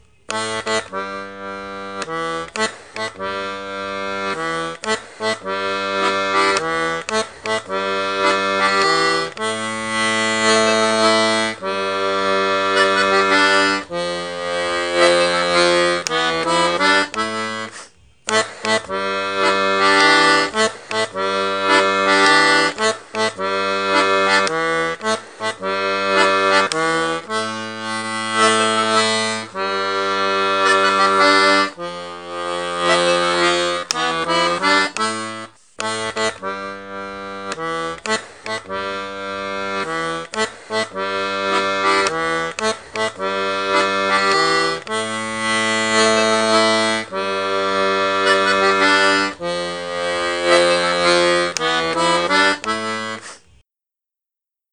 l'atelier d'accordéon diatonique
accompagnement n°1 main gauche et main droite